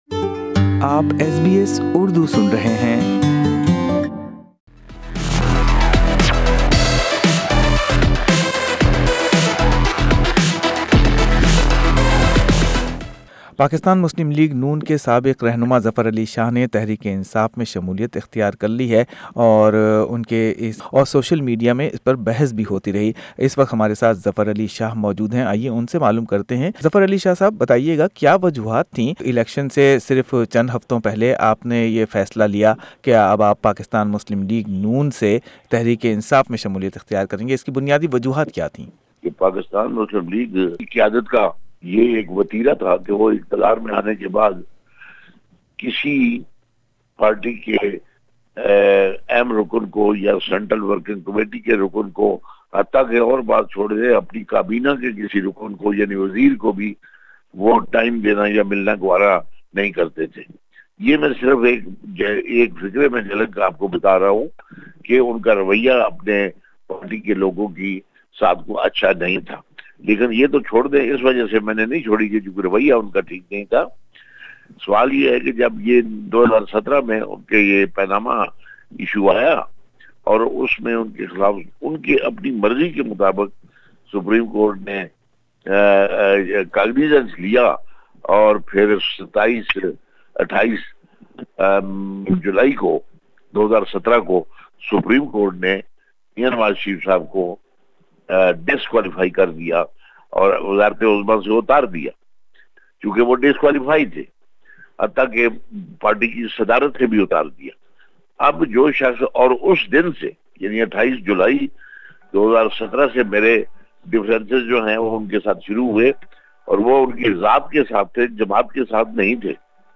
Why Zafar Ali Shah quit Nawaz league and joined PTI (Pakistan Tehreek-e-Insaf) just few weeks before election (Audio). He talked to SBS Urdu about his decision in detail.